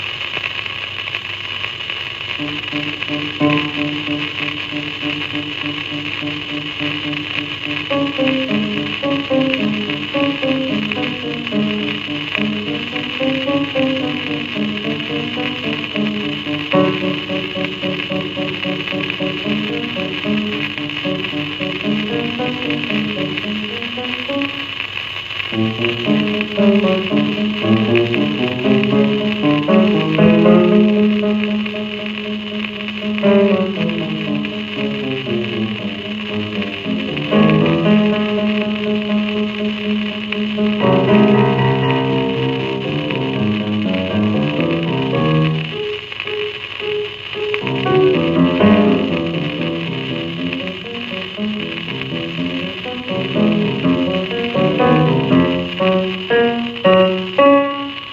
両面共に作曲家による自作自演